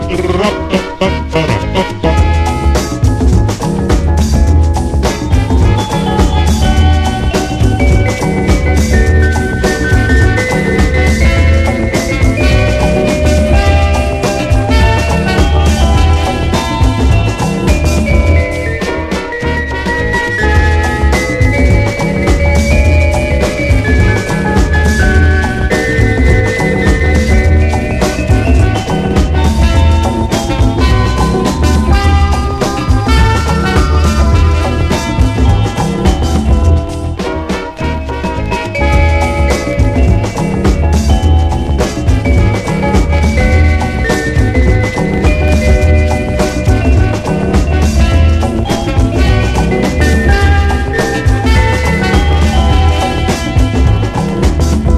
新世代シンセ・ブギー/エレクトロ・ファンク名曲をカップリング！